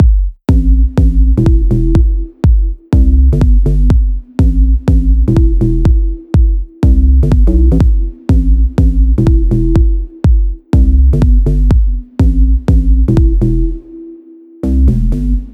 Main Loop